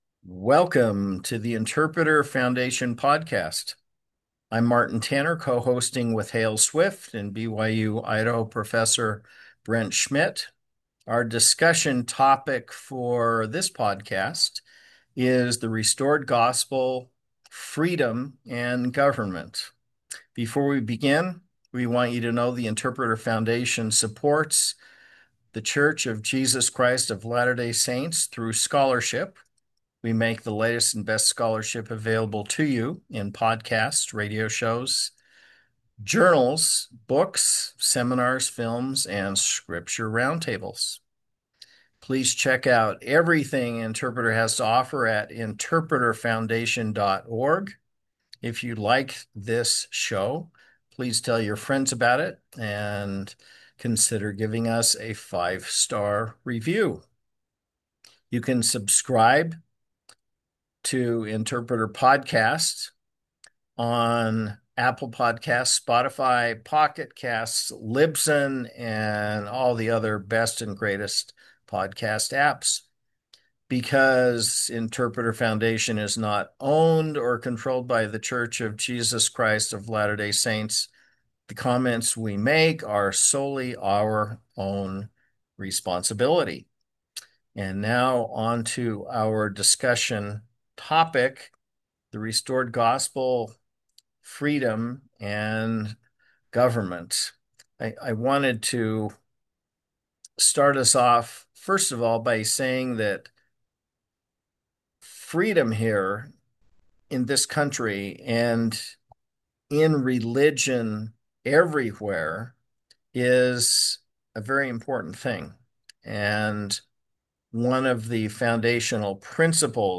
You can listen to or download the discussion segment of the podcast episode below.